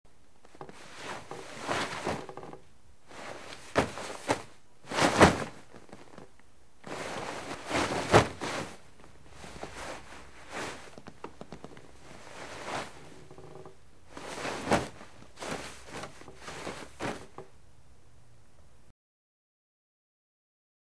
PullingBackBlanket PE381801
Pulling Back Blanket On Bed With Bed Frame Creaks